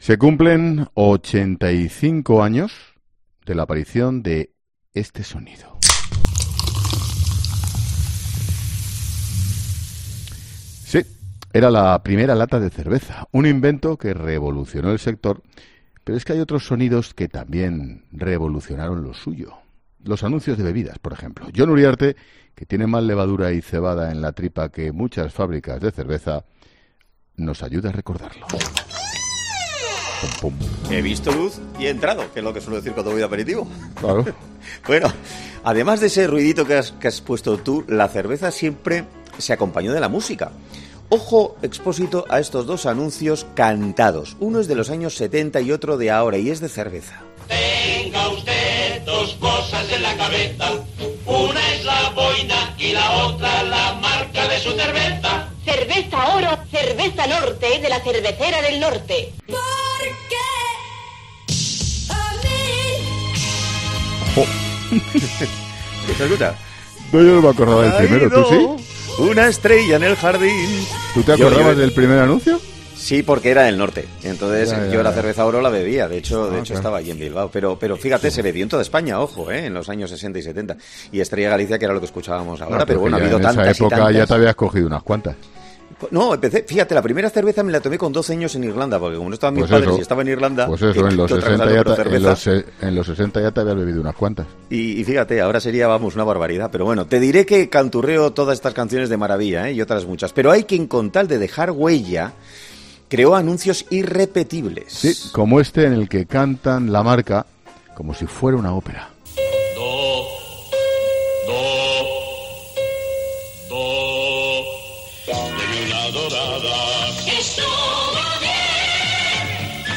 Se cumplen 85 años de la aparición de este sonido -efecto lata que se abre-.